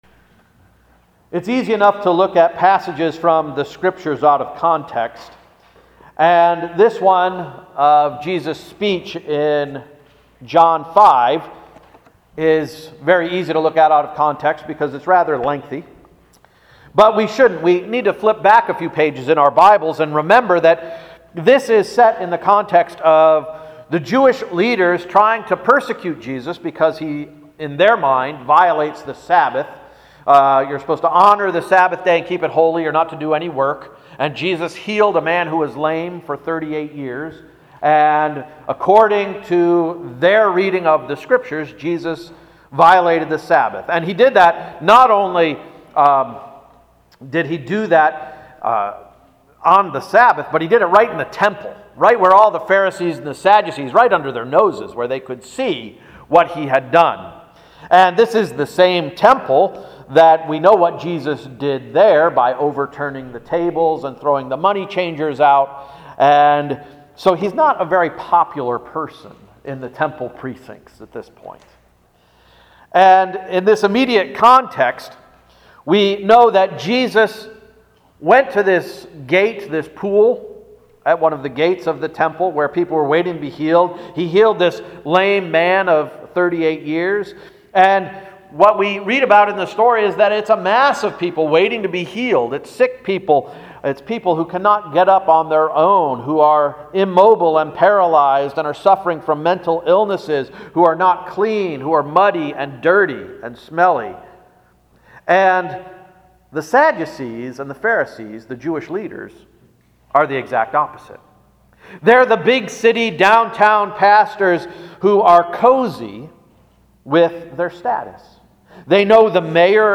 October 29, 2017 Sermon
reformation-500-sermon.mp3